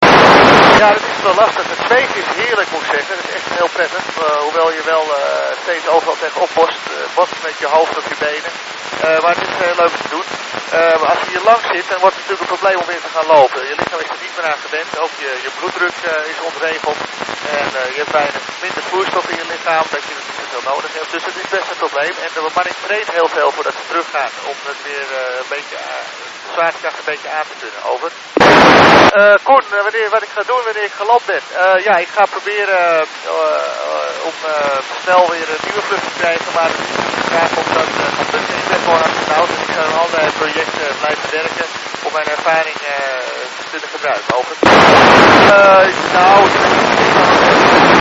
Apparatuur: Handheld programmeerbare radio scanner "Realistic Pro-38" met zelfgemaakte dipool antenne (twee horizontale draadstangen van 50 cm lengte).
Astronaut Andre Kuipers sprak tijdens een educatieve vraag & antwoord sessie op de 145.8 MHz HAM frequentie in het Nederlands met een groep schoolkinderen en studenten welke zich hadden verzameld op de TU Eindhoven.
Deze passage was veel minder gunstig, maximale hoogte 18 graden en ISS niet dichterbij dan 1000 km. Ik kon niettemin ongeveer 1.5-2 minuten van het gesprek redelijk volgens, een deel ervan zelfs zeer goed.